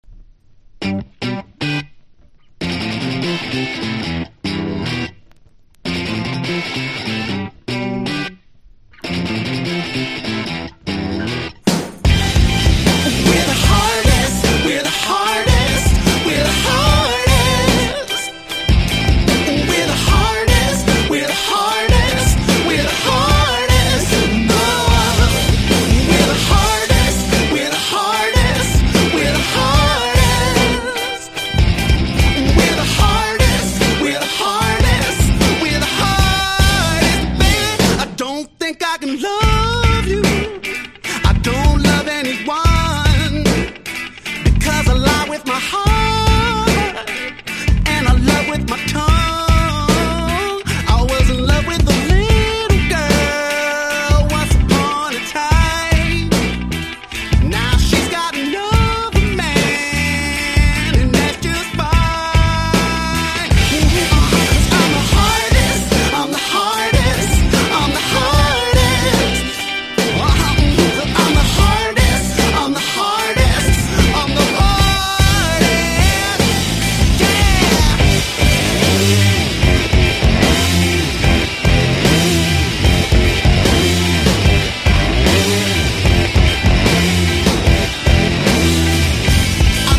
ブルー・アイド・ソウルとディスコ・パンクを掛け合わせたようなポップ･チューン。